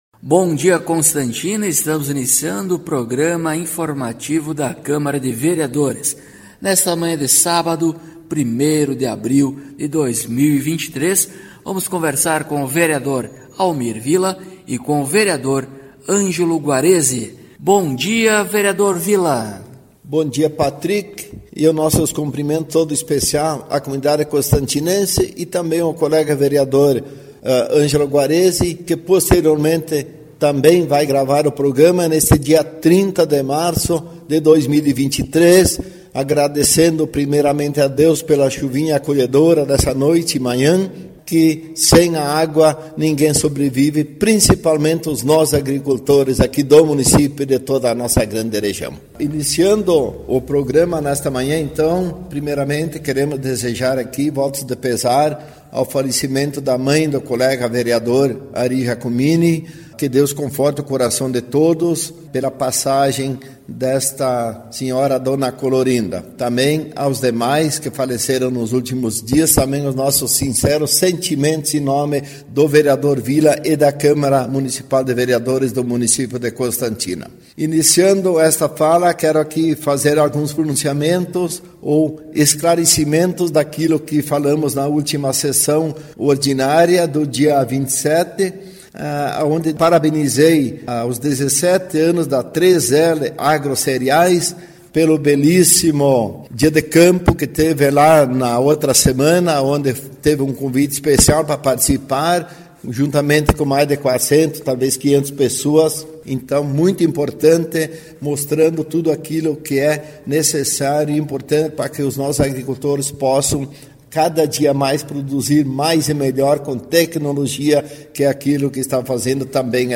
Acompanhe o programa informativo da câmara de vereadores de constantina com o Vereador Almir Villa e o Vereador Ângelo Guaresi.